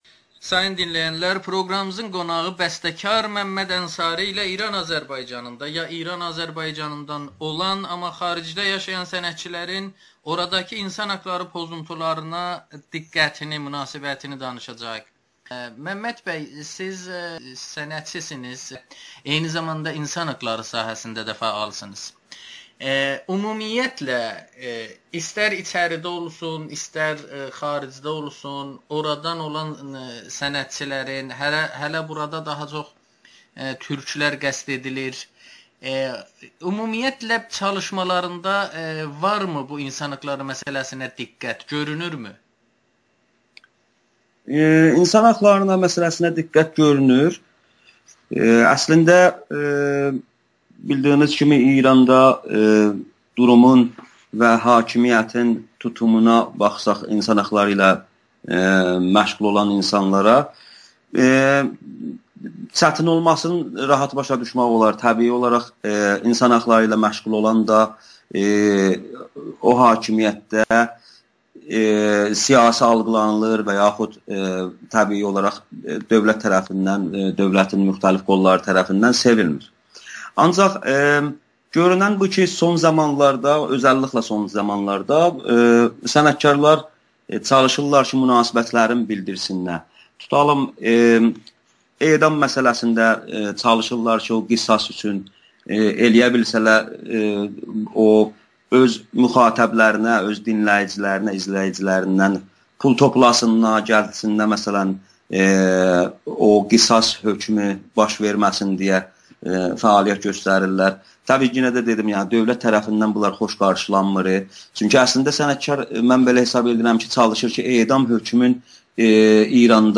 Hüquq müdafiəçiləri sənətkarları cəlb etməyin yollarını axtarmalıdır [Audio-Müsahibə]